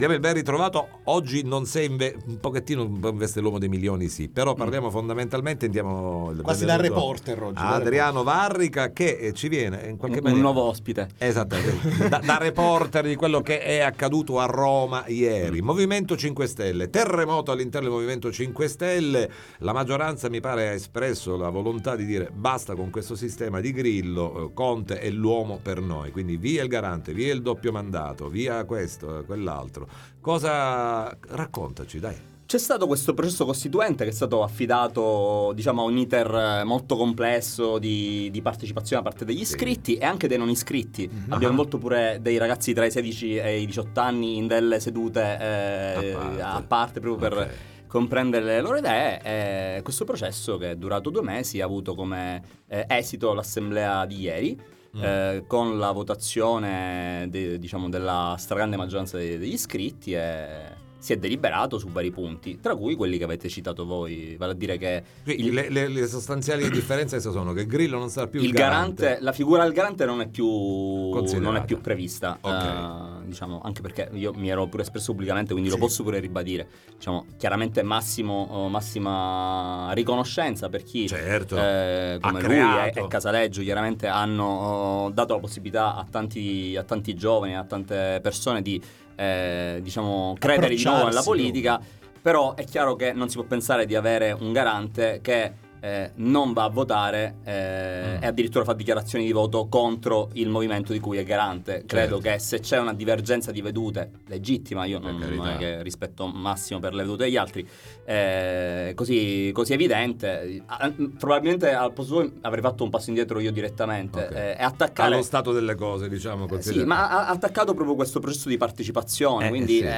Beppe Grillo cacciato dal M5S: ora il fondatore prepara la guerra legale, ne parliamo con Adriano Varrica, dep. ARS M5S